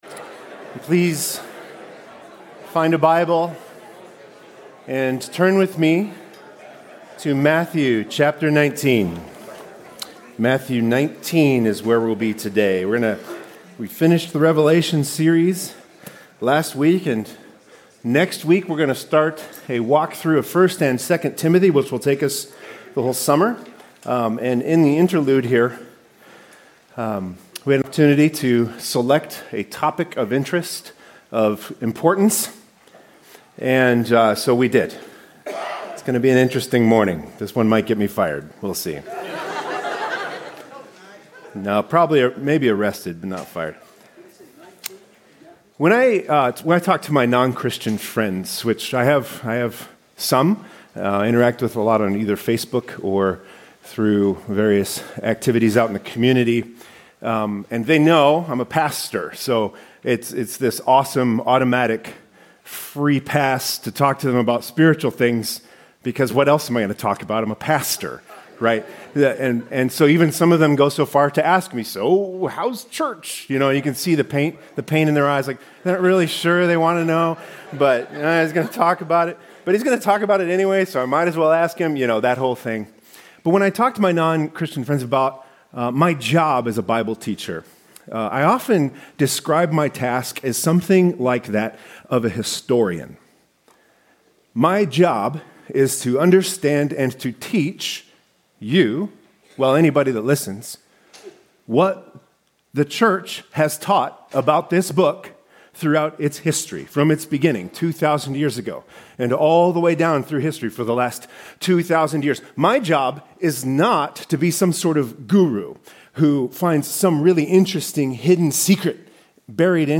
Full sermon manuscript